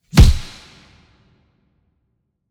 TM88 FunkKick7.wav